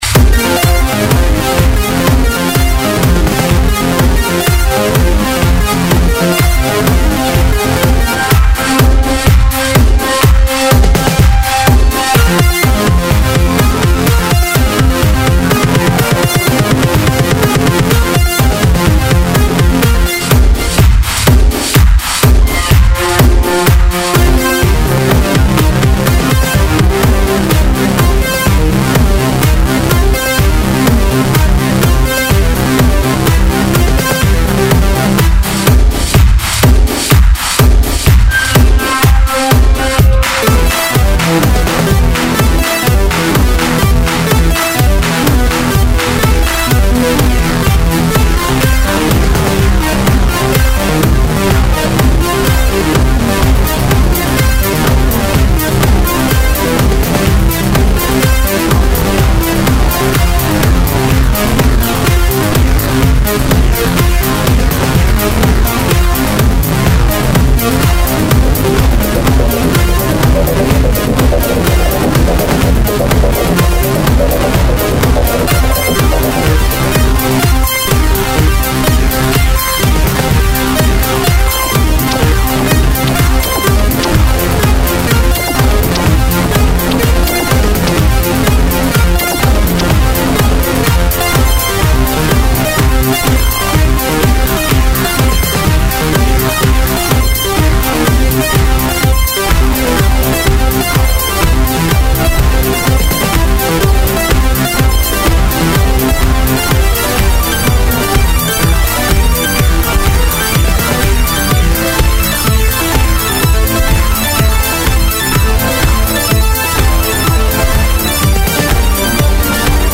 Ambient
Trance
Industrial